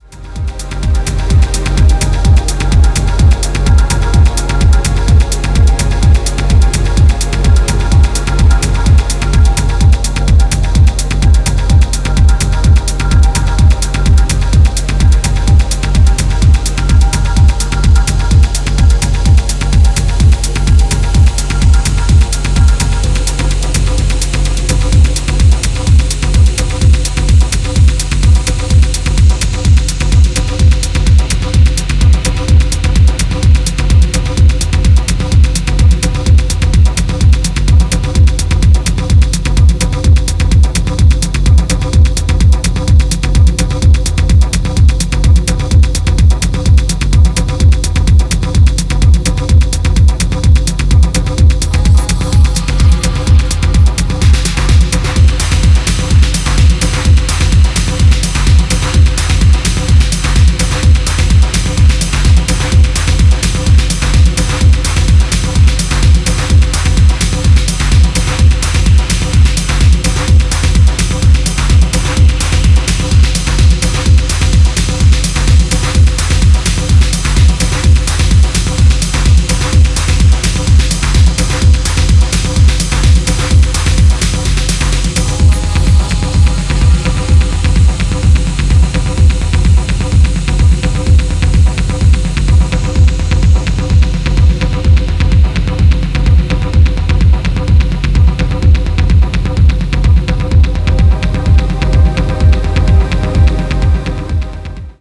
techno duo